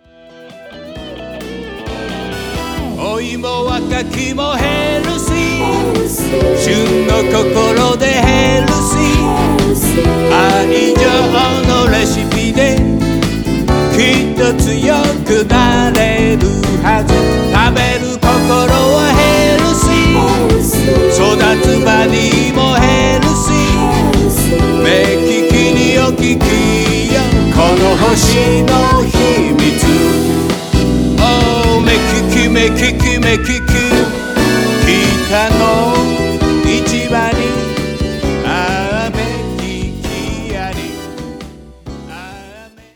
シンボルソング
市内のアマチュアバンドが、この運動のＰＲのために、歌を作ってくれました。